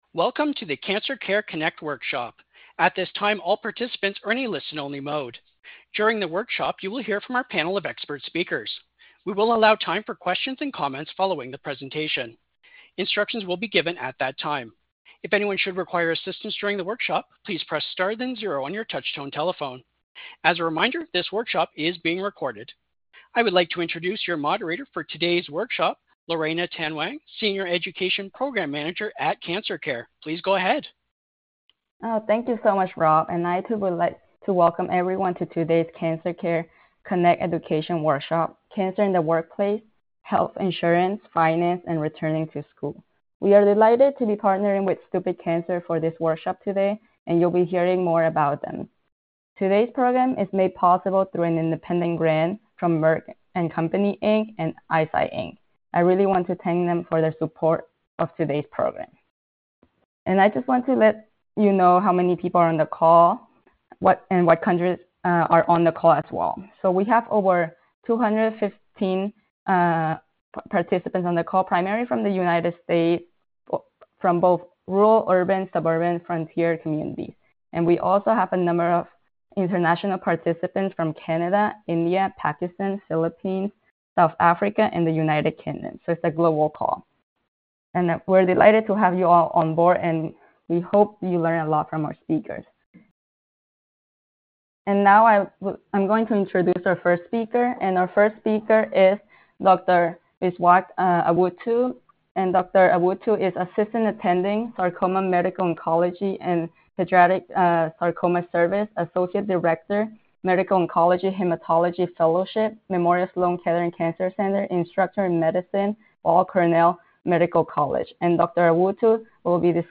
Questions to Ask Our Panel of Experts
This workshop was originally recorded on December 13, 2024.